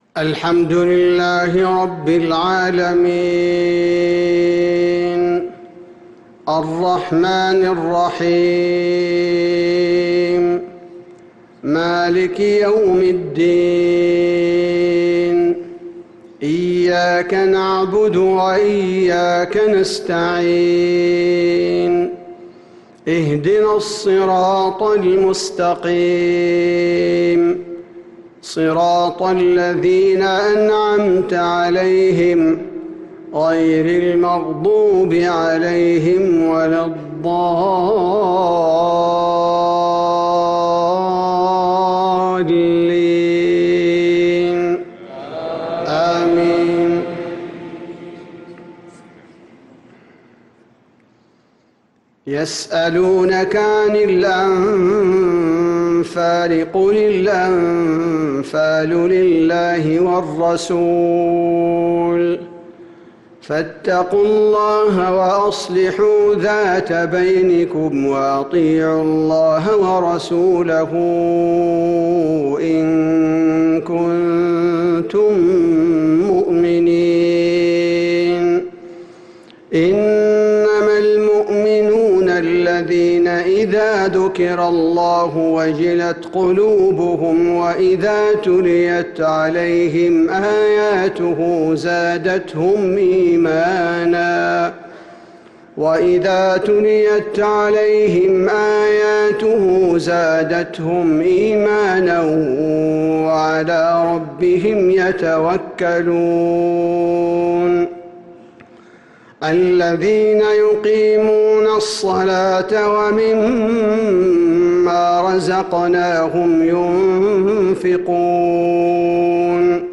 صلاة المغرب للقارئ عبدالباري الثبيتي 10 ذو القعدة 1445 هـ